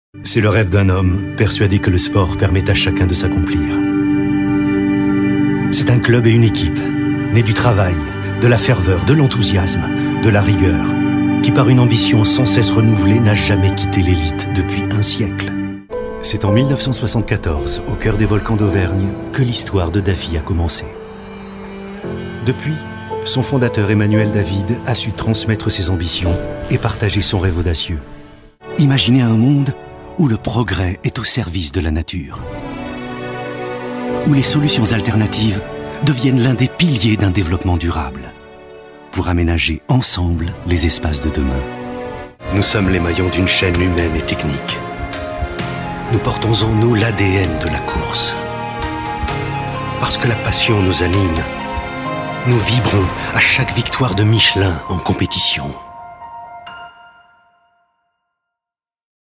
Démo voix off Institutionnel
30 - 60 ans - Baryton-basse